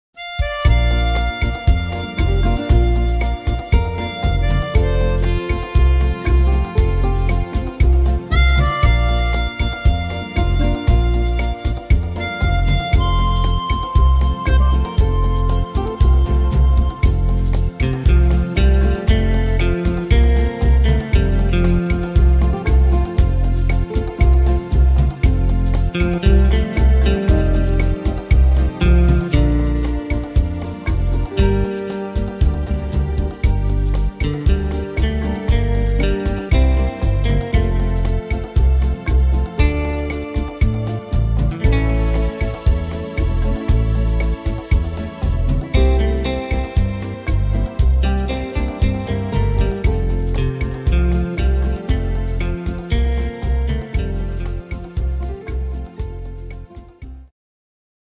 Tempo: 117 / Tonart: F-Dur